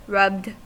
Ääntäminen
Ääntäminen US Haettu sana löytyi näillä lähdekielillä: englanti Käännöksiä ei löytynyt valitulle kohdekielelle. Rubbed on sanan rub partisiipin perfekti.